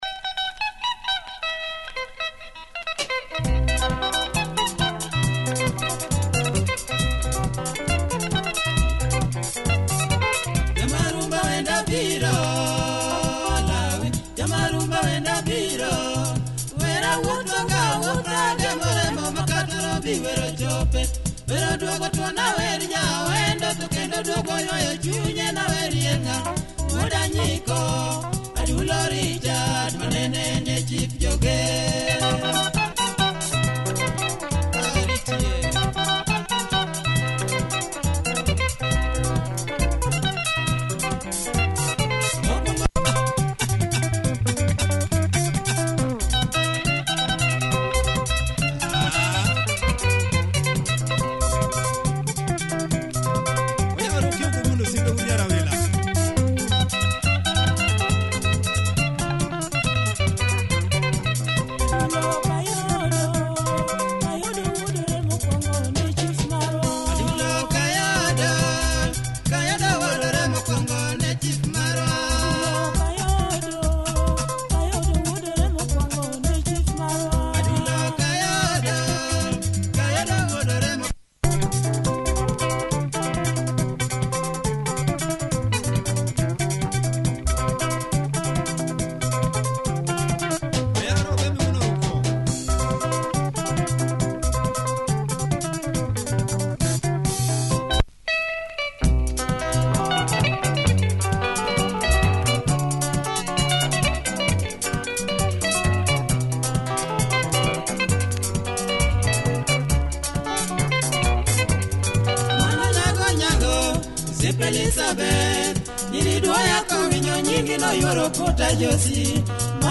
Pumping luo benga